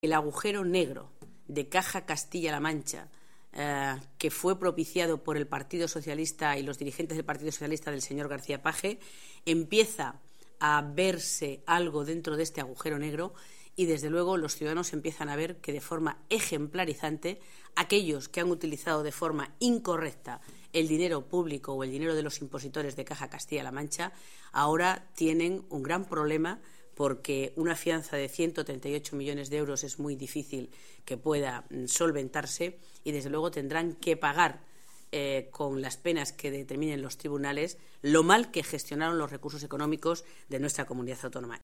Riolobos_sobre_CCM.mp3